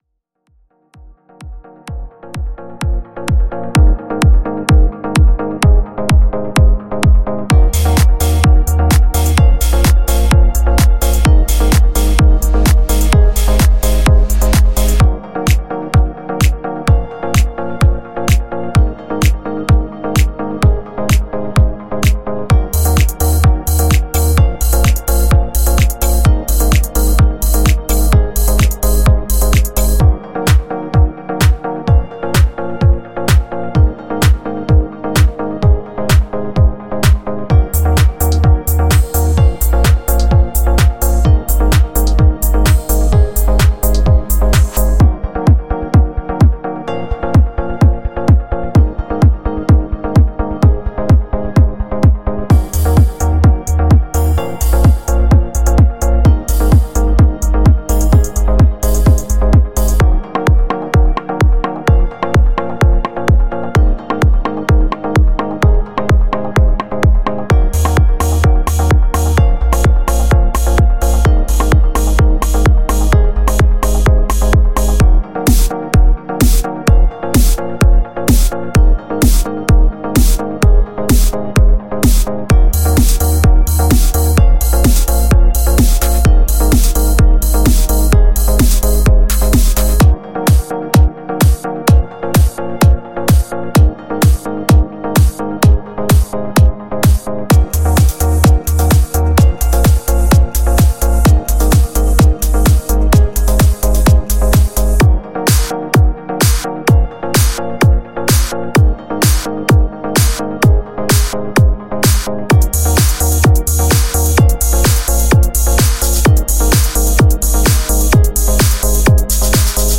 包括用于制作每个套件的鼓采样–踢，圈套，拍手，封闭和开放的踩-，骑行和敲击声。
• 25 Drum Kits
• 120 Drum Loops